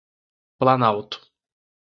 Tariamas kaip (IPA) /plaˈnaw.tu/